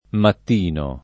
matt&no] s. m. — nell’uso più ant., spec. poet., anche maitino [mait&no] e matino [mat&no]: Levati da maitino [l$vati da mmait&no] (Giacomo da Lentini); Ma sospirando andai matino et sera [ma SSoSpir#ndo and#i mat&no e SS%ra] (Petrarca) — mattino confuso con mattina nell’uso burocr. (aperto al mattino dalle ore 9.30 alle 12.30); riservato nell’uso lett. ai titoli (Il mattino di G. Parini, i vari giornali Il mattino) e agli accoppiam. con sera per intendere l’intera giornata (sera e mattino, dal mattino alla sera: ma anche mattina); nell’uso pop. e tradizionale, detto piuttosto della «prima mattina», in locuzioni (sul mattino; di buon mattino) e in proverbi (il buon dì si conosce dal mattino; il mattino ha l’oro in bocca)